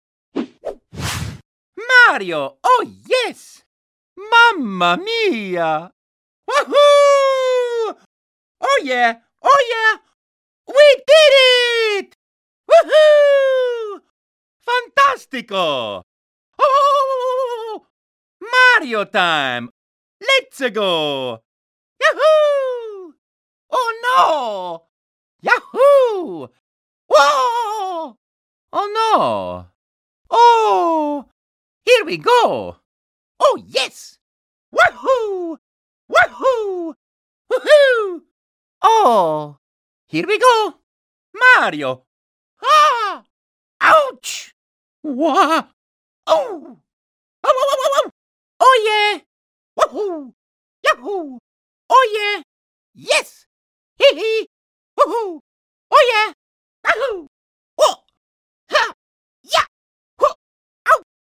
All Mario Voice Clips • sound effects free download
All Mario Voice Clips • Mario Sports Mix • Voice Lines • Nintendo Wii • 2010